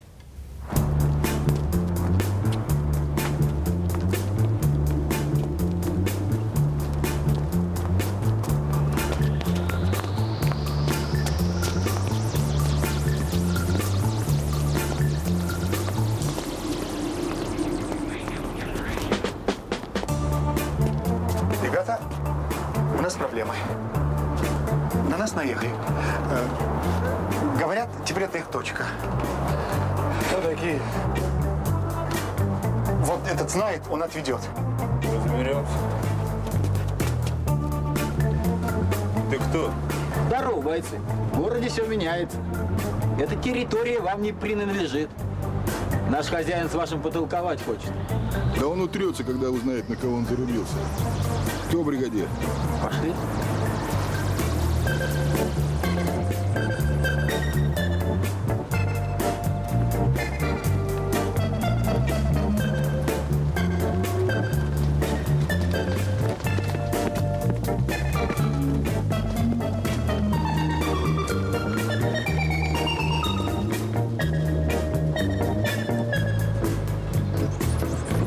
инструментальных композиций